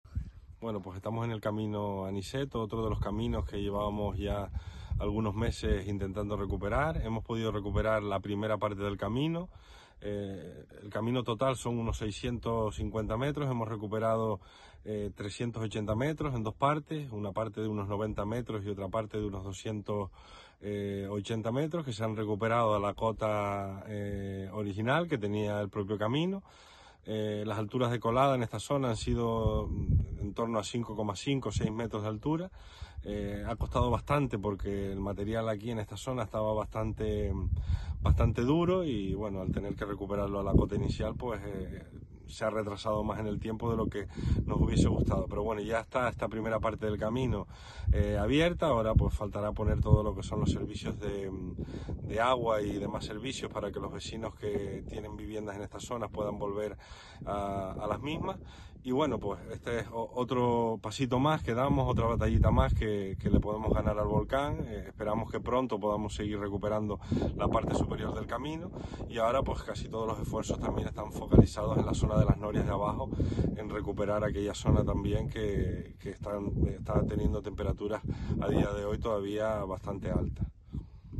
Declaraciones audio Borja Perdomo.mp3